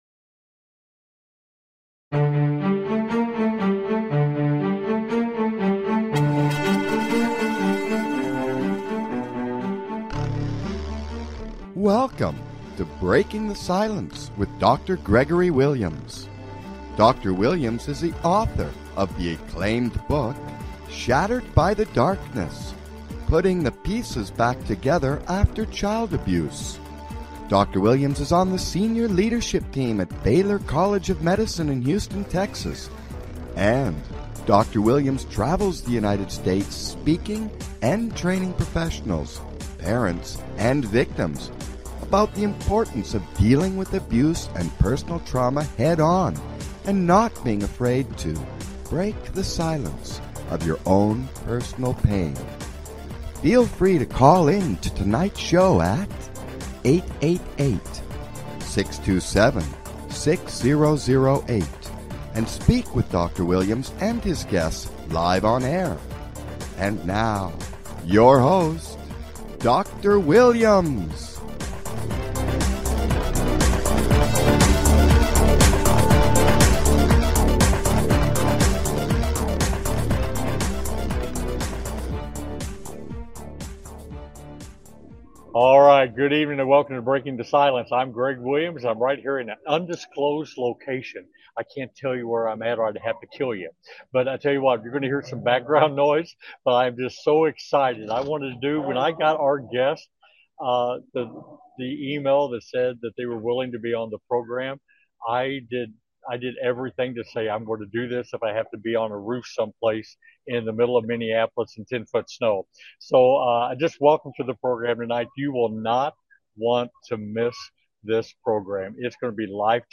With Special Guest, Dave Pelzer, Author of "A Child Called 'It'"